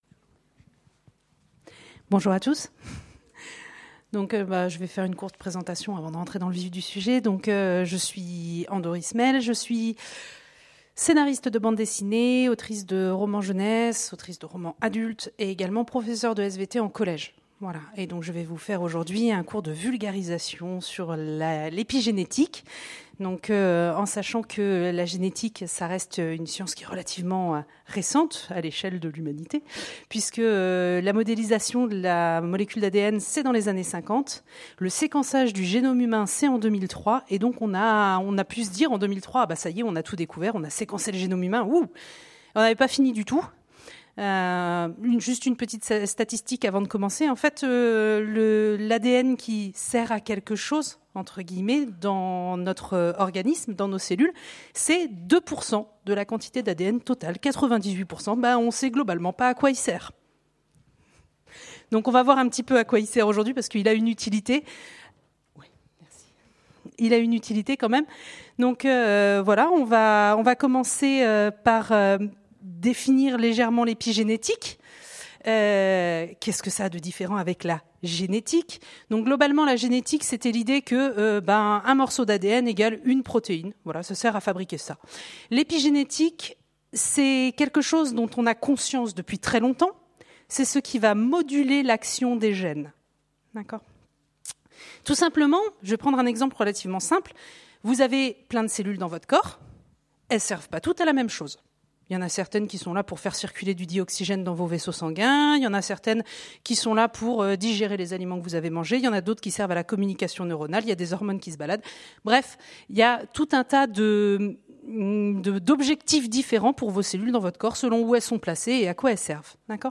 Conférence L'épigénétique pour les nuls enregistrée aux Utopiales 2018